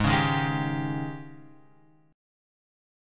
G# Major 7th Suspended 4th
G#M7sus4 chord {4 4 1 1 x 3} chord